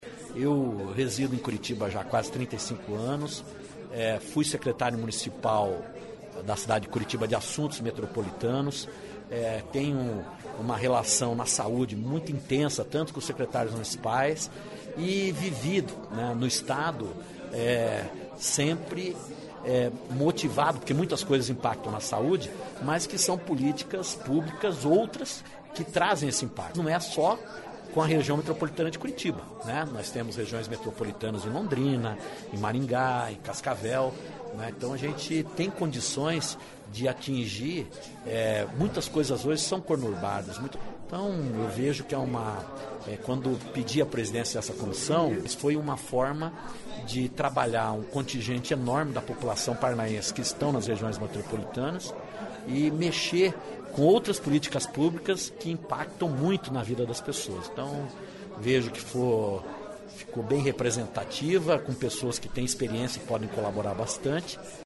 Ouça o presidente da Comissão de Relações Federadas e Assuntos Metropolitanos
O presidente é Michele Caputo Neto (PSDB).Ouça a entrevista do parlamentar.